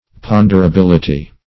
Search Result for " ponderability" : The Collaborative International Dictionary of English v.0.48: Ponderability \Pon`der*a*bil"i*ty\, n. [Cf. F. pond['e]rabilit['e].] The quality or state of being ponderable.